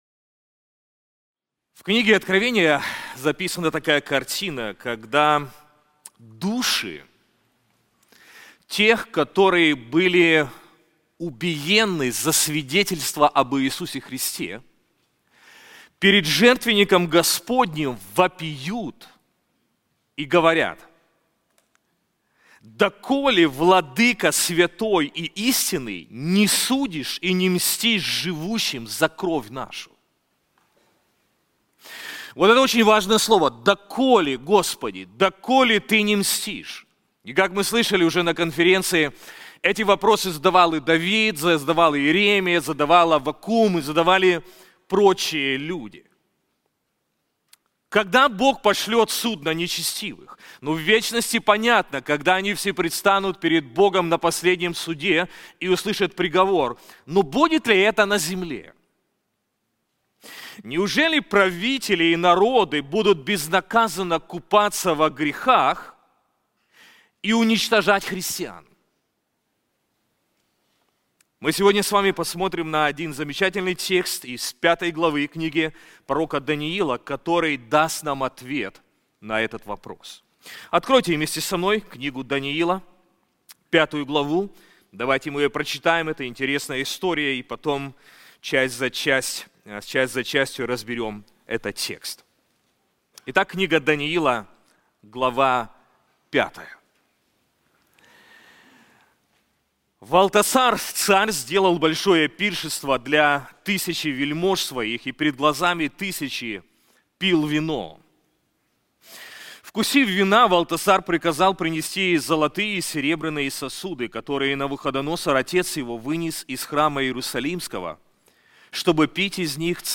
На конференции "Владыка истории" мы рассмотрим книгу пророка Даниила, каждая глава которой ясно показывает, что история находится в руках Бога. Лишь осознав, что нашим миром управляет не хаос, а всевластный Бог, мы сможем уверенно смотреть в будущее, оставаясь верными и усердными в служении.